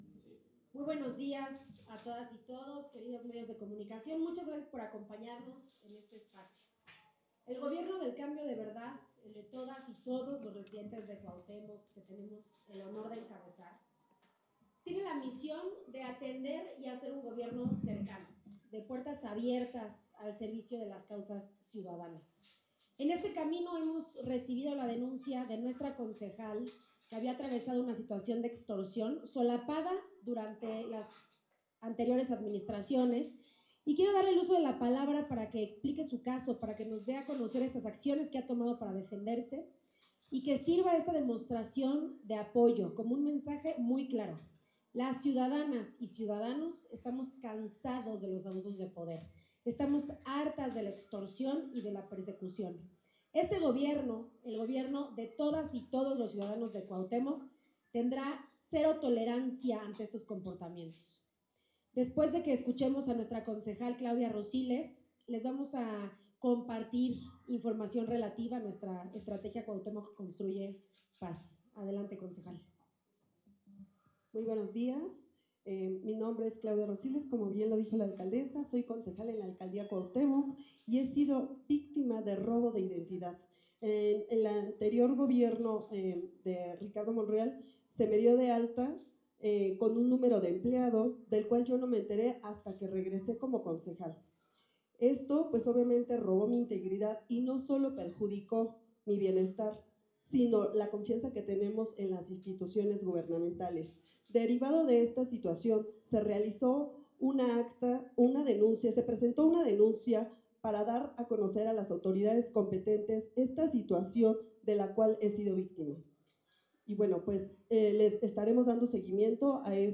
La alcaldesa de Cuauhtémoc, Alessandra Rojo de la Vega, presentó los resultados de la estrategia integral Cuauhtémoc Construye Paz, con la que su gobierno ha avanzado en la recuperación de espacios públicos y la seguridad en la demarcación. En conferencia de prensa, destacó que, pese a haber recibido amenazas recientemente, su compromiso con la justicia y la seguridad permanece firme.
CONFERENCIA-ALE-ROJO-DE-LA-VEGA-27-ENERO.m4a